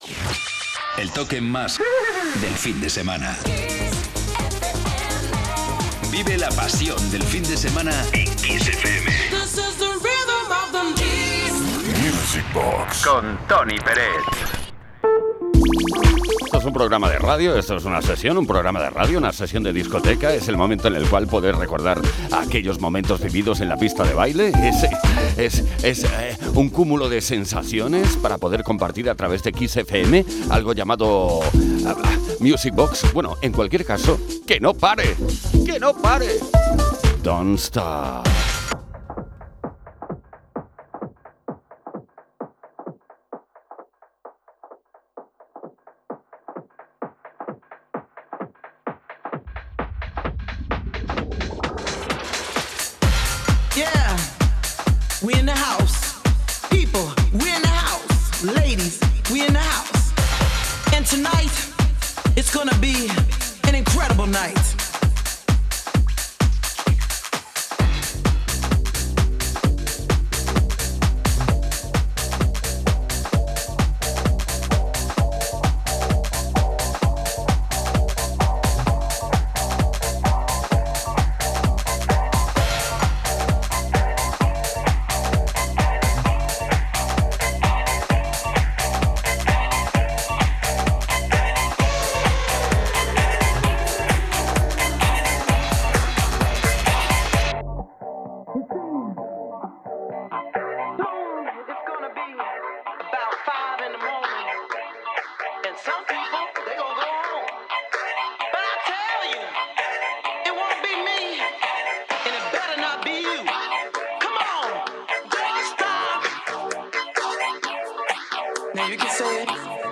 Lo mejor de los 80 y los 90 hasta hoy